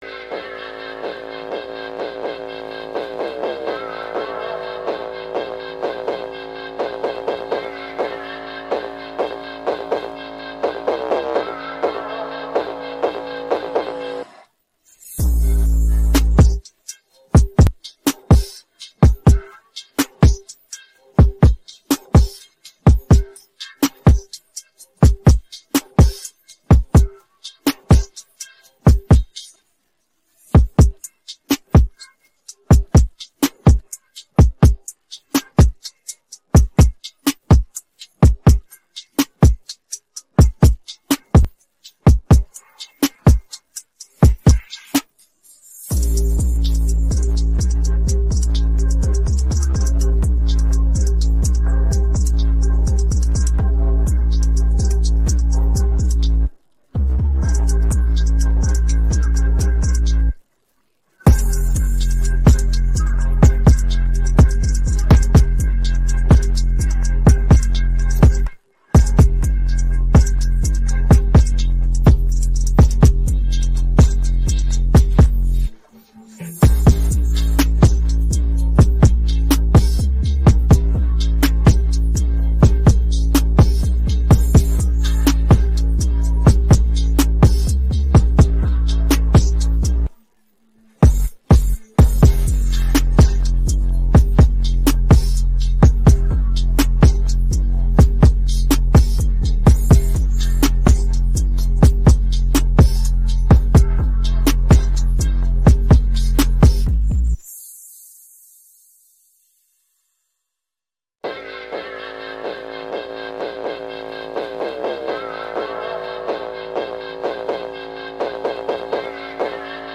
rap караоке 11
Українські хіти караоке